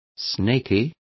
Complete with pronunciation of the translation of snaky.